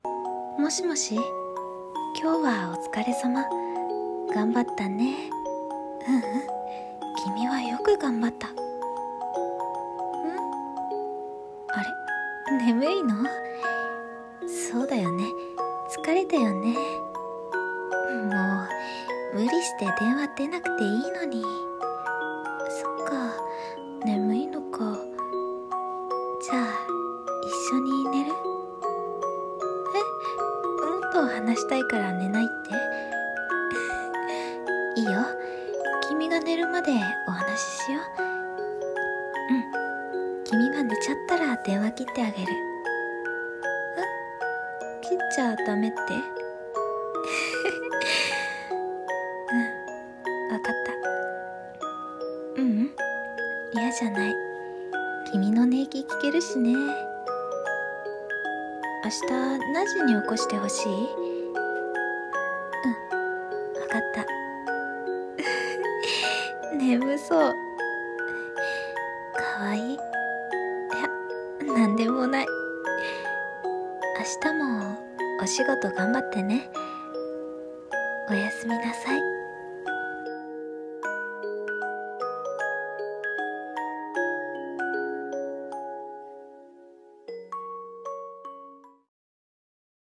【1人用声劇台本】